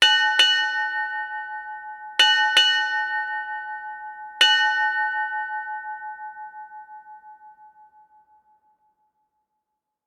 Five Bells,Ship Time
5-bells bell ding maritime nautical naval sailing seafaring sound effect free sound royalty free Sound Effects